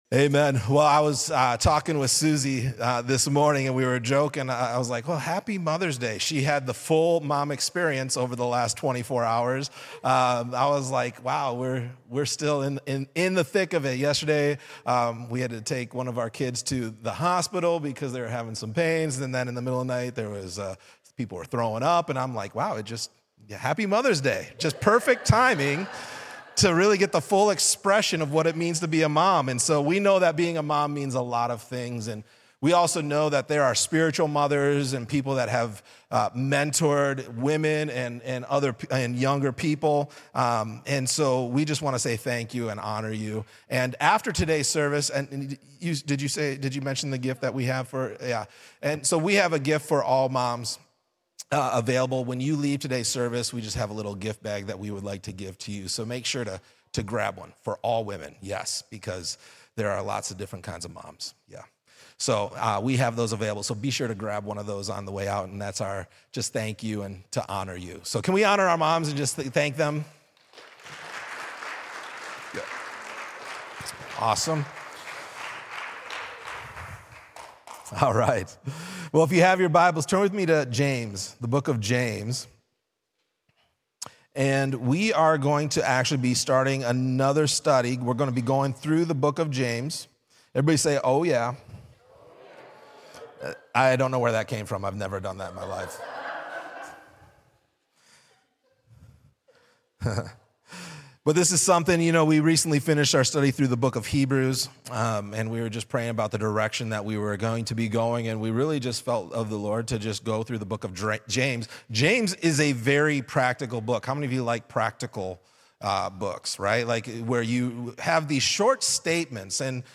A message from the series "The Book Of James."